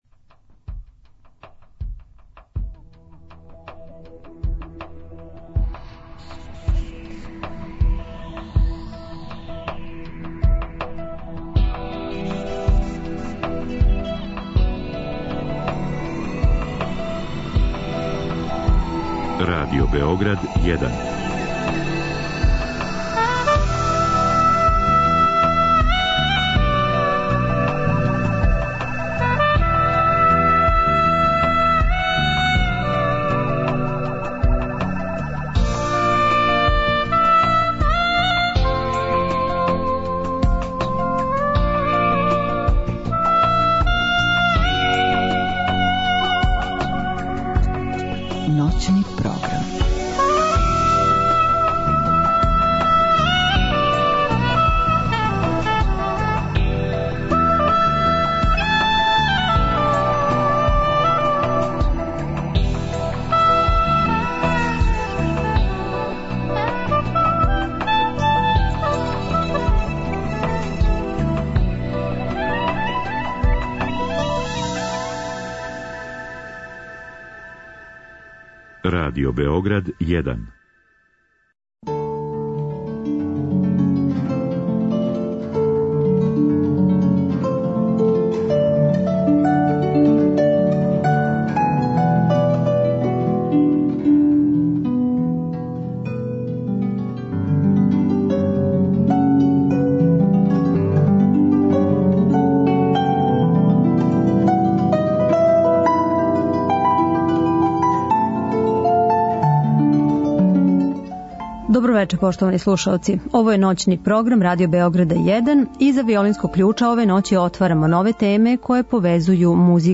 Гост у студију